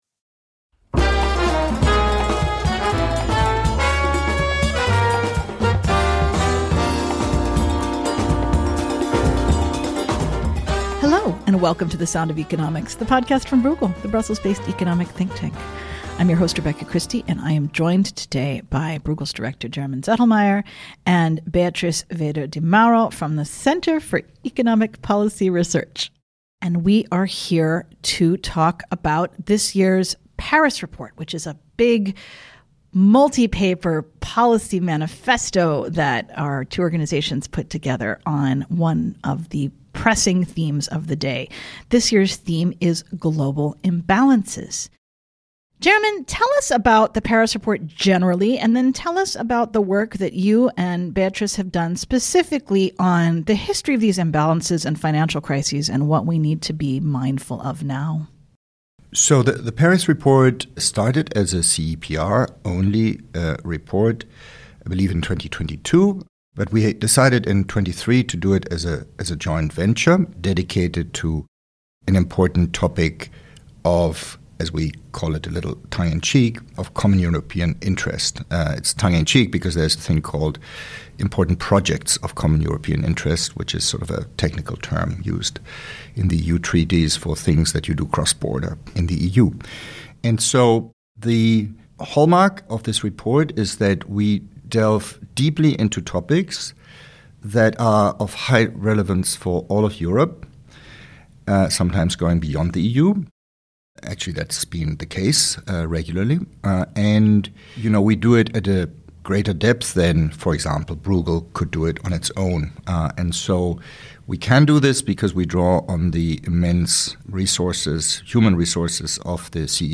To launch the 2026 edition of the CEPR Paris report, they discuss why trade deficits and surpluses are soaring and what risks should worry us most. US external and public debt is at a historic high, raising new questions about the dollar as a safe haven.